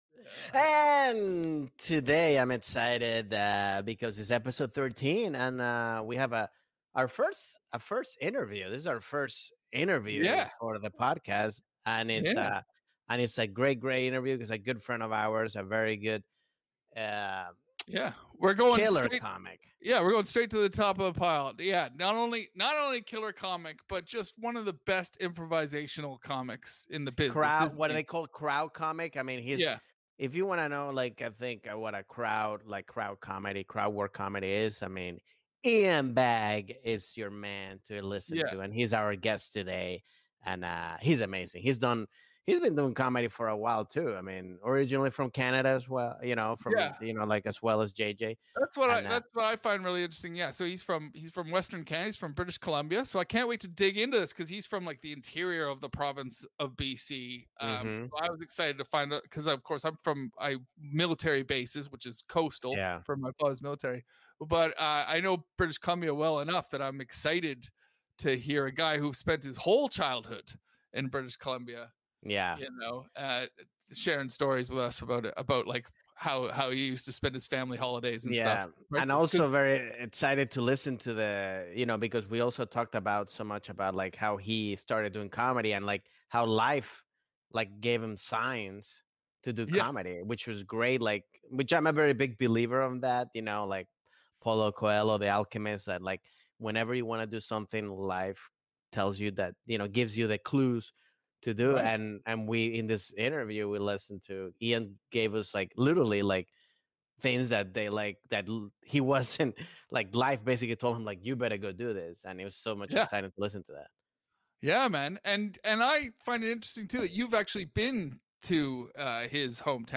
This week the guys are joined by hilarious comedian IAN BAGG!